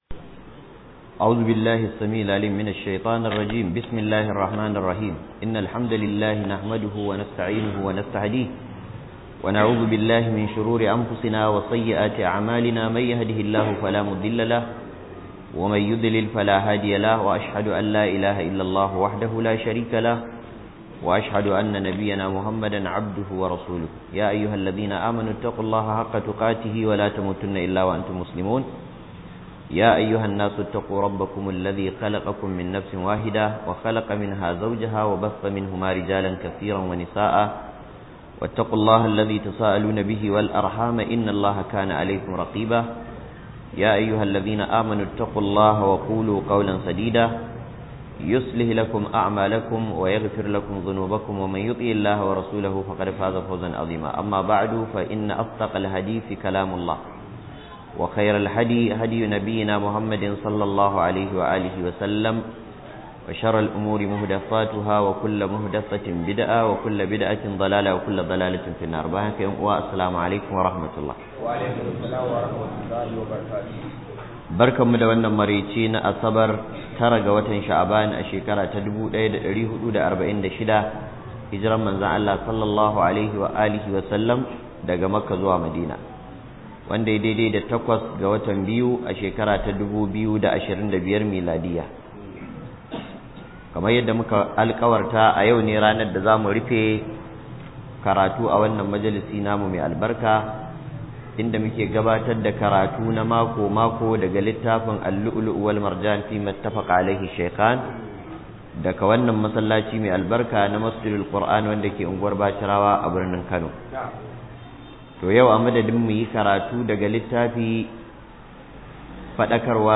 IN BA KAYI BANI WURI: Jana'izar 'Yan Qala Qato - MUHADARA